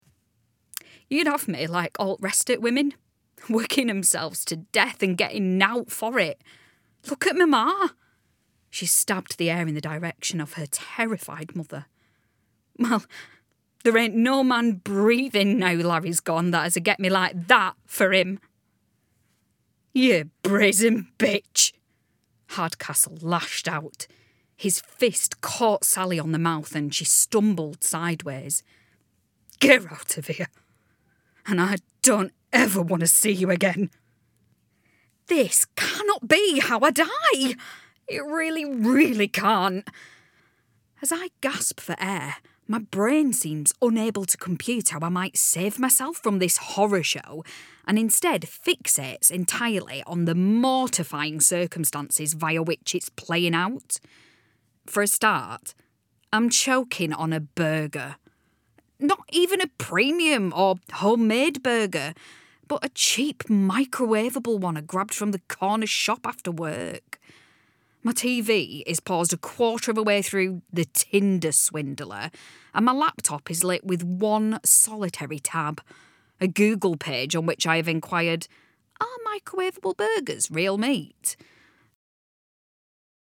30s-40s. Female. Lancashire. Studio
Audiobook